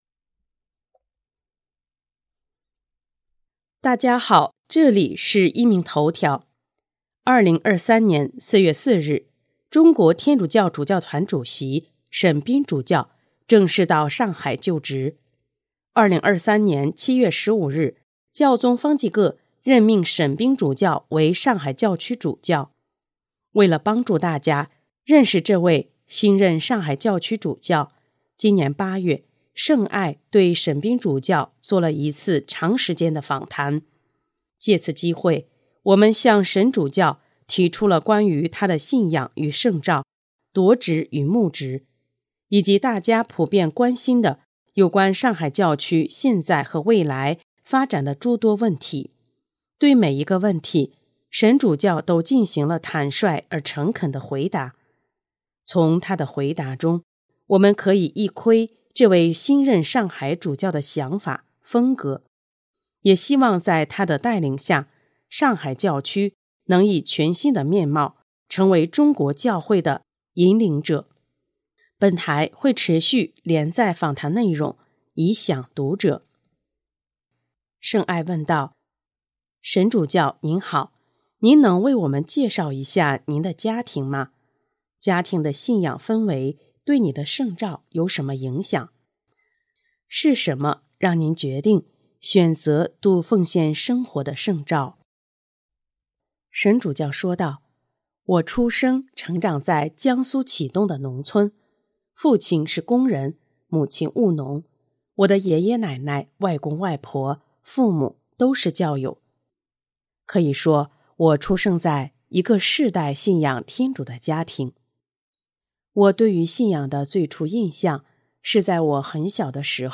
【壹明头条】| 上海沈斌主教专访(一)：家庭的信仰气氛和圣召起源